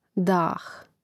dȃh dah